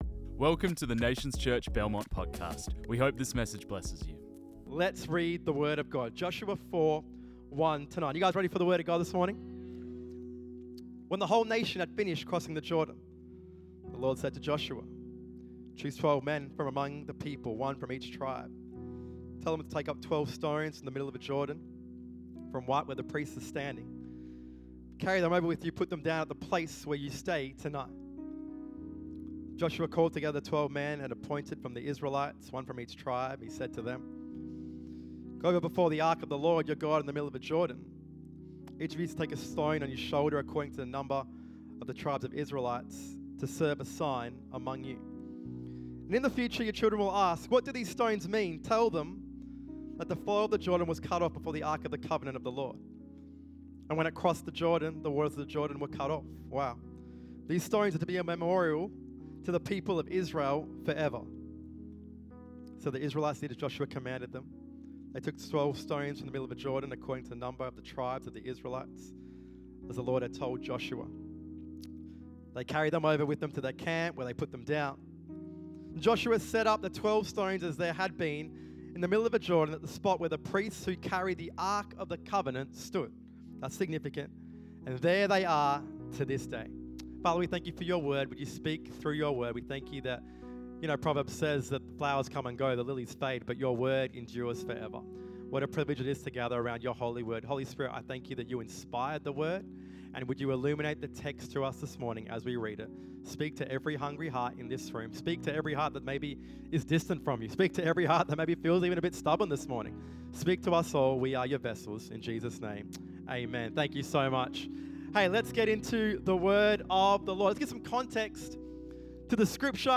This message was preached on 25 May 2025.